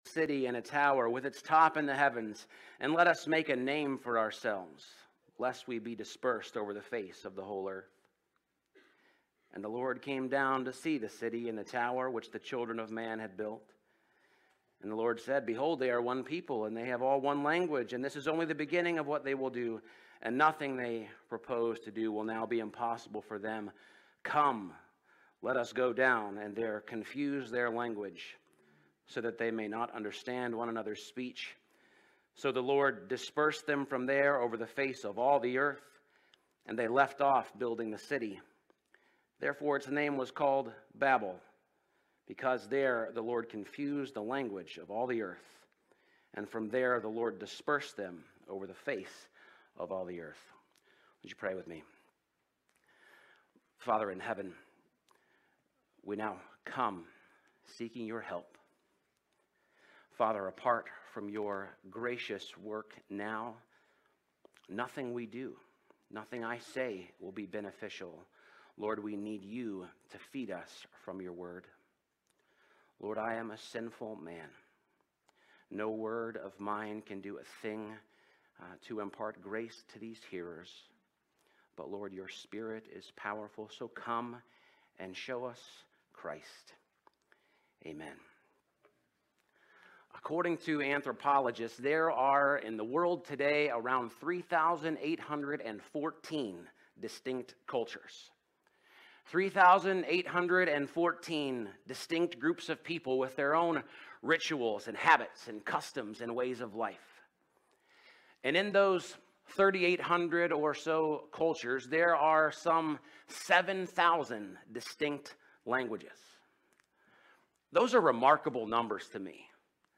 Sunday Worship – October 13 of 2024 – “The Gift of Peace”